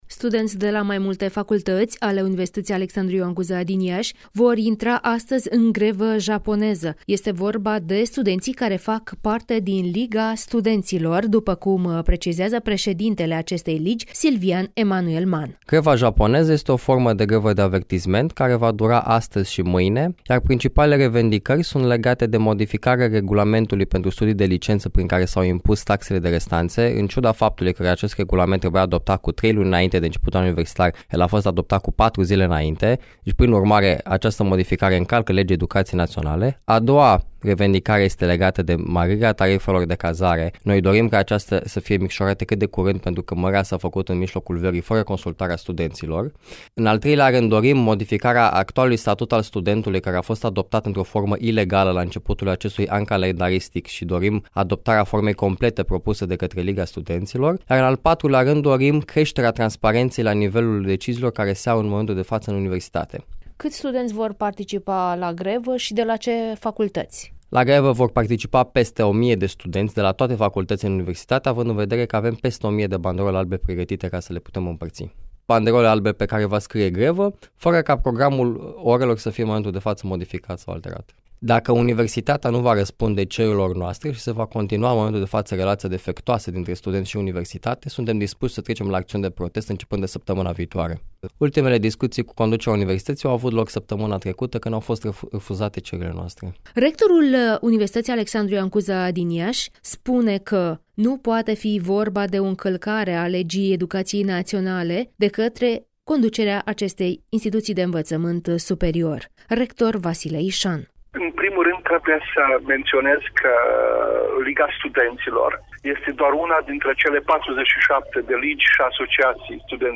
(REPORTAJ) Grevă japoneză la Universitatea Cuza din Iași